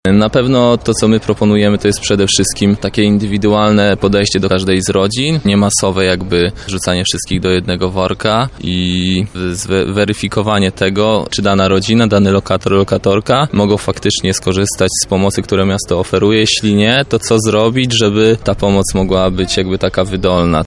sniadanie-prasowe.mp3